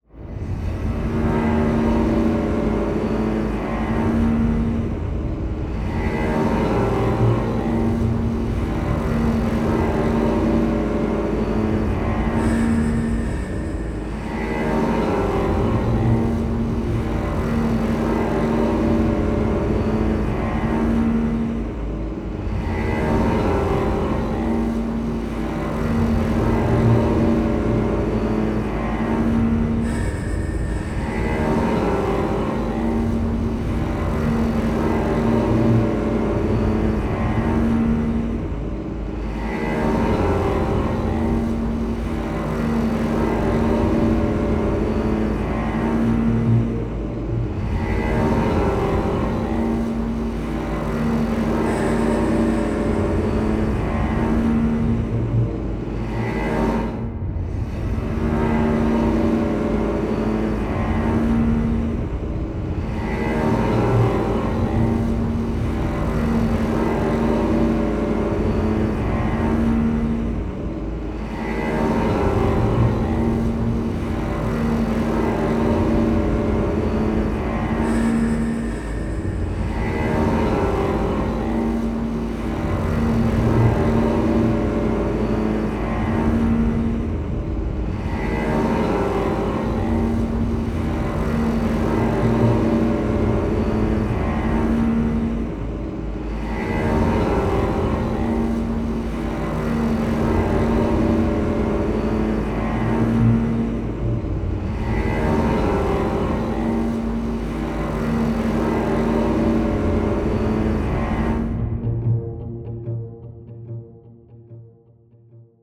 free horror ambience 2
ha-simplestring_1.wav